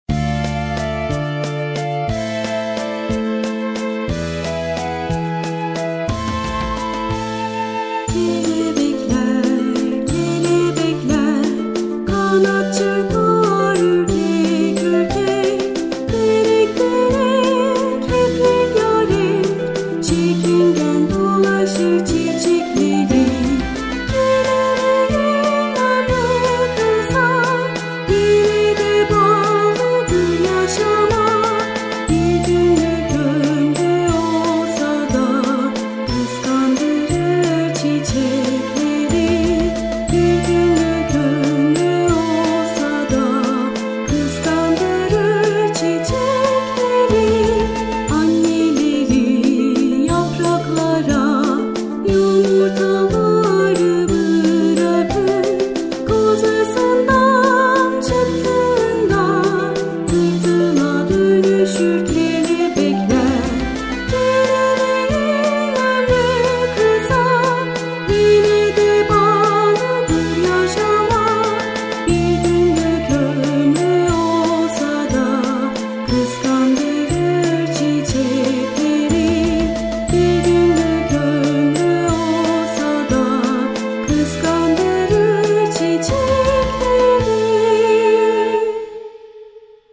(CD Tanıtım Şarkısı - FULL SÜRÜM)